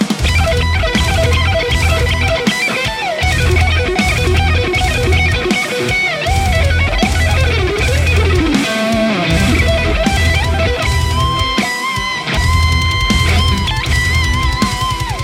This one does it with great balance and alot of bite!
Lead Mix
RAW AUDIO CLIPS ONLY, NO POST-PROCESSING EFFECTS
Hi-Gain